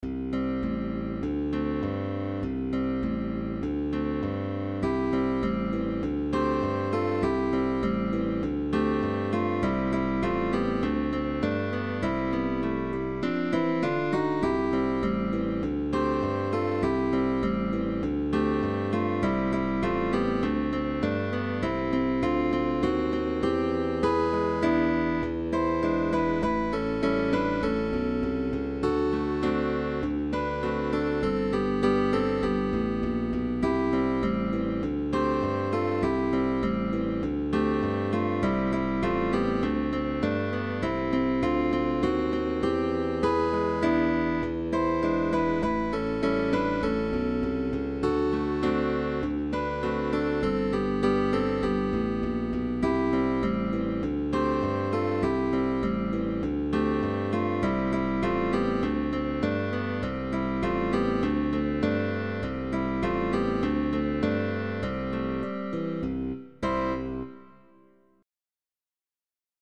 Main theme
With optional bass.